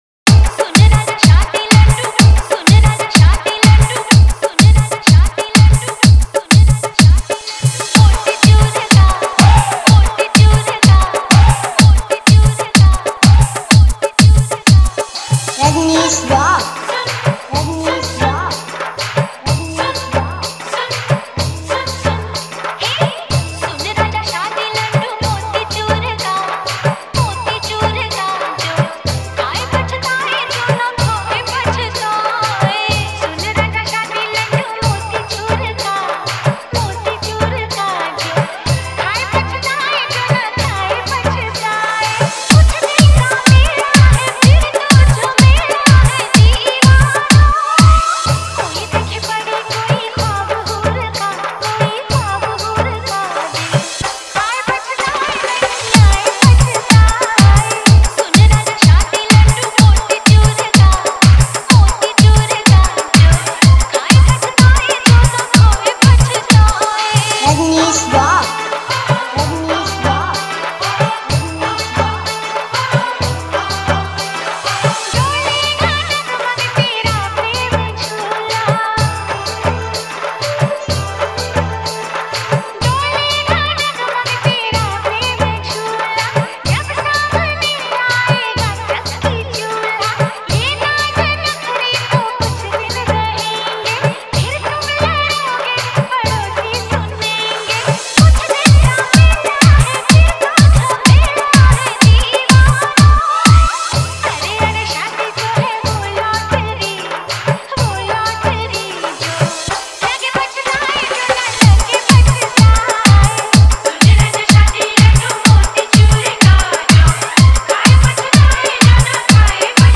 Wedding Dj Song